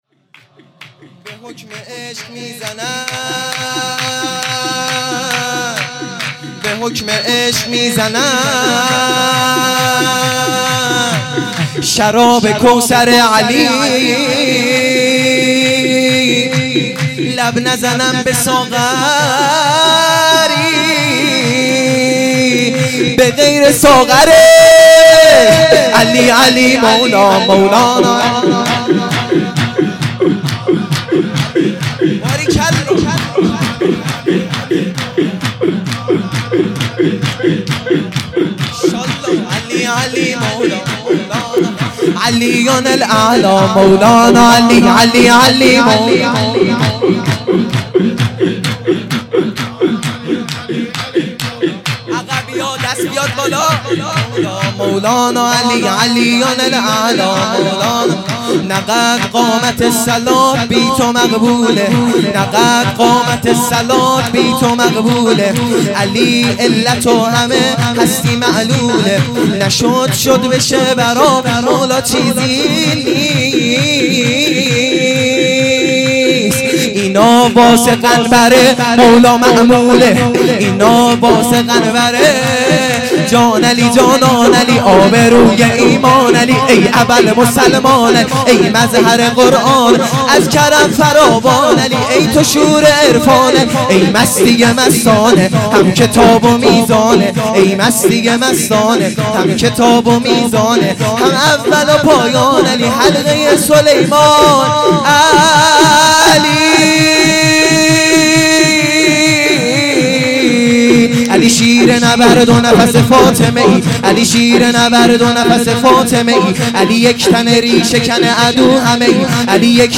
سرود | نه قد قامت الصلاه بی تو
میلاد امام حسن عسکری (ع)